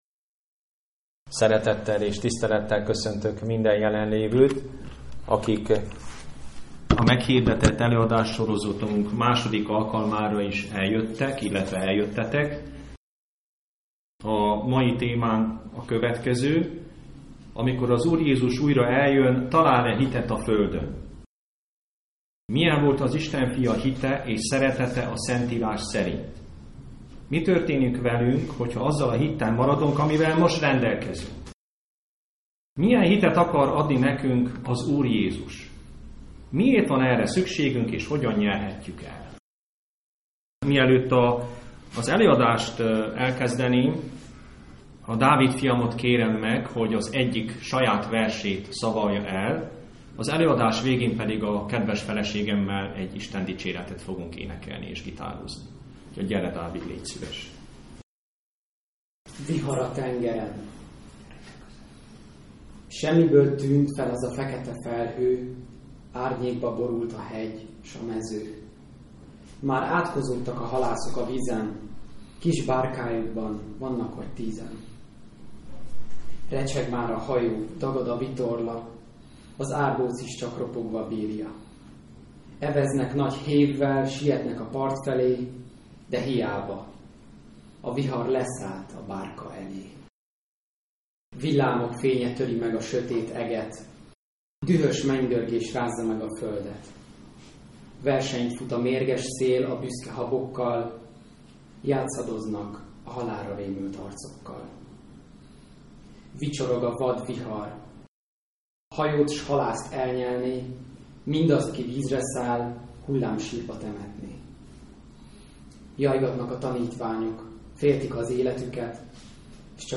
II. előadás. Jézus hitéről és arról, hogy milyen hitet akar adni nekünk az Úr.